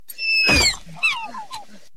Play, download and share Dilophosaurus Playful original sound button!!!!
dilophosaurus-playful.mp3